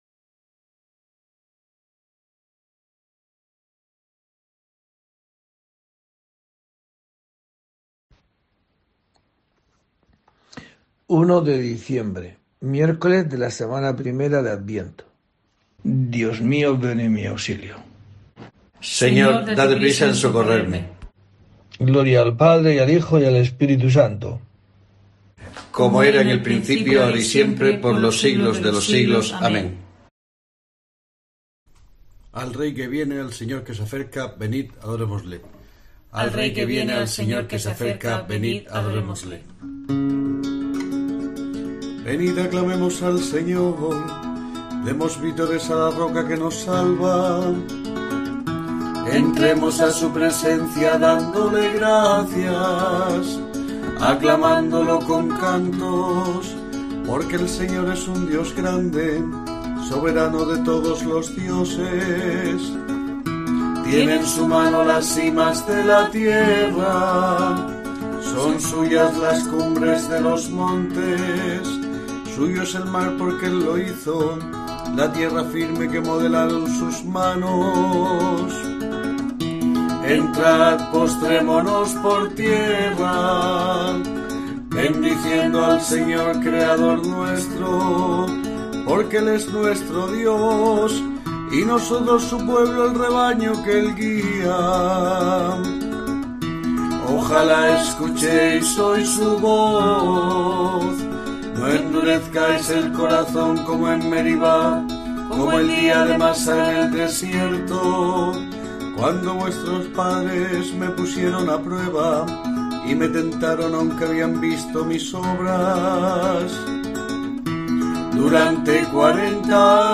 Laudes